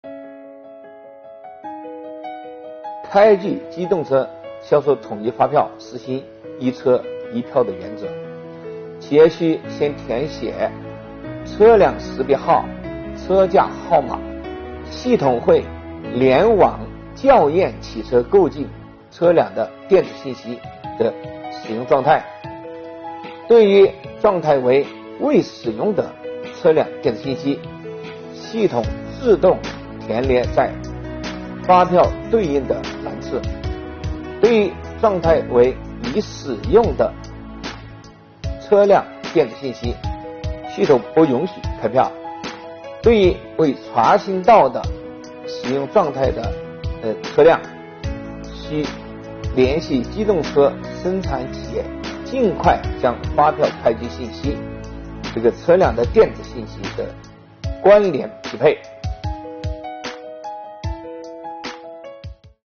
近日，国家税务总局推出“税务讲堂”课程，国家税务总局货物和劳务税司副司长张卫详细解读《办法》相关政策规定。